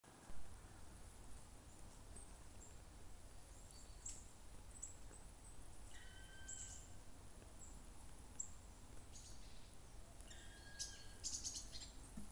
Birds -> Woodpeckers ->
Black Woodpecker, Dryocopus martius
StatusVoice, calls heard